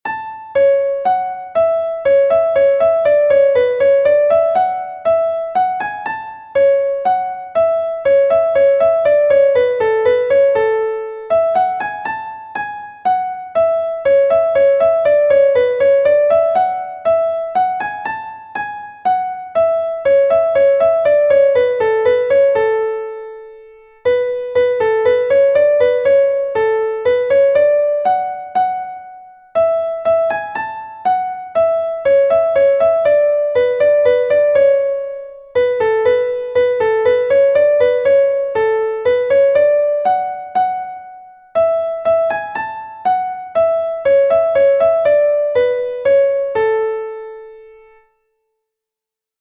Bourrée Pevar Den II est un Bourrée de Bretagne enregistré 1 fois par Pevar Den